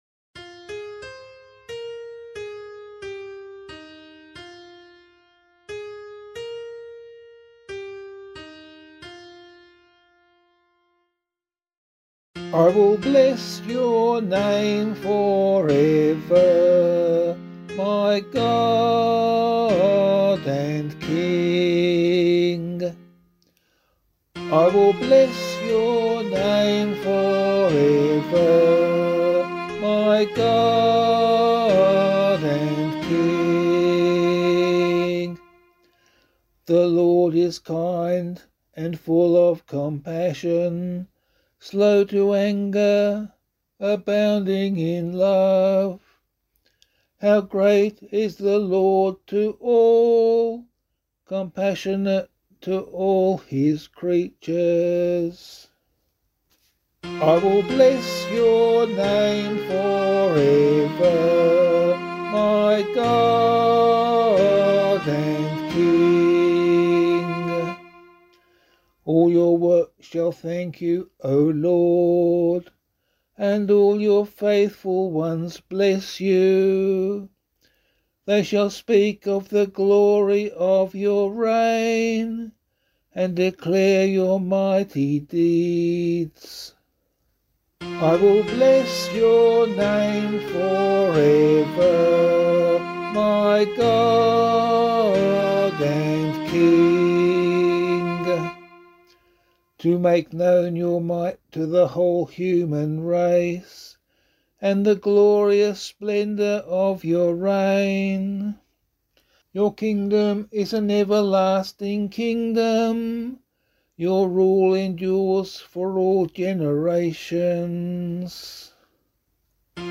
027 Easter 5 Psalm C [APC - LiturgyShare + Meinrad 2] - vocal.mp3